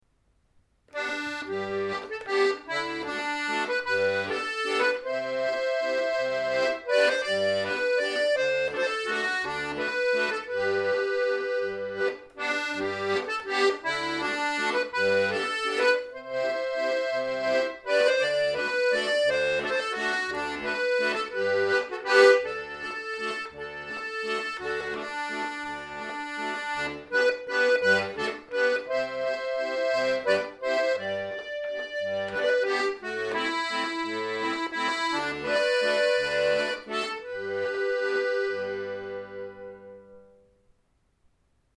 partitions et tablatures, fichiers midi pourl�accord�on diatonique. apprentissage et paroles d'airs, de danses, de chants bretons, celtiques, marins ou populaires.
Valse